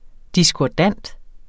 Udtale [ diskɒˈdaˀnd ]